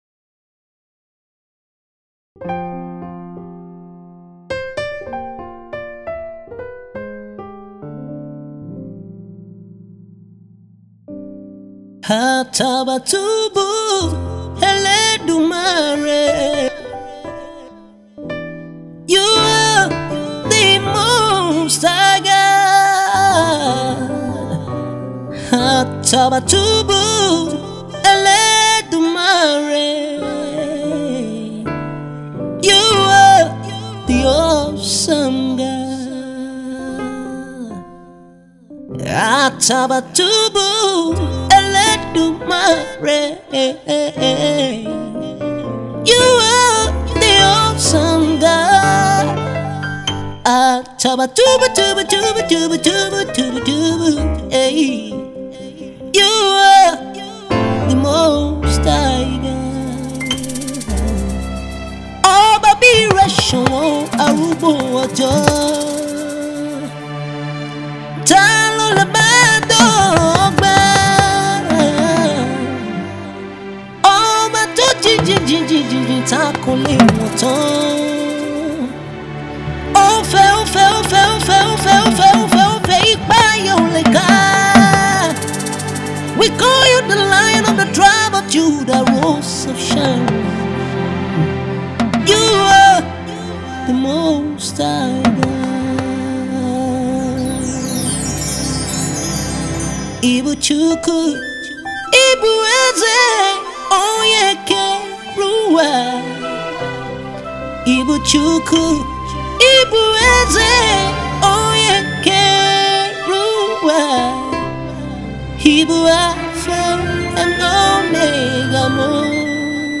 dynamic worship song